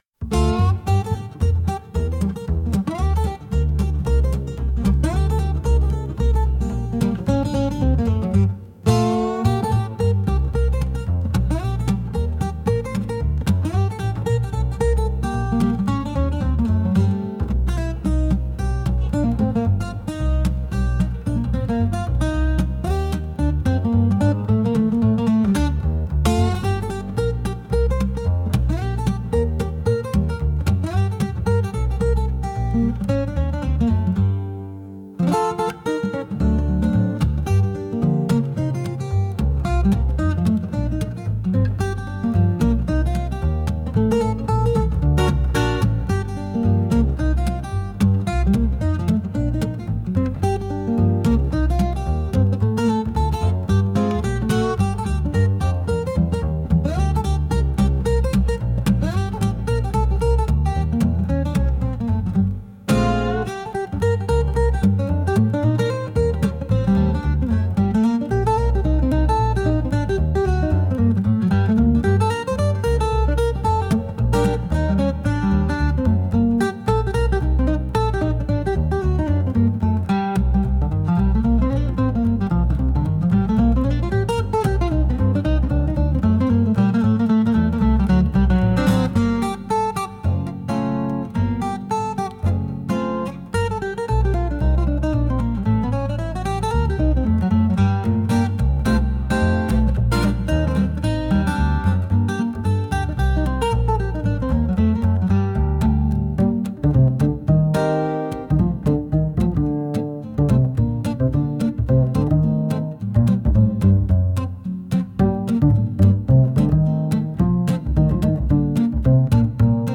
Instrumental - - Real Liberty media - 3.02 .